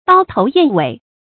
刀头燕尾 dāo tóu yàn wěi 成语解释 喻笔锋劲利。